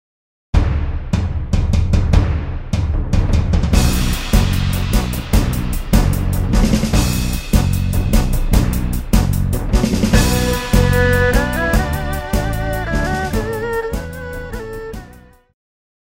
爵士
套鼓(架子鼓)
乐团
演奏曲
融合爵士
独奏与伴奏
有节拍器
来自东方的二胡
有着细腻的特质
有着豪迈的本色
开场的大鼓节奏
锣鼓点与爵士鼓的对话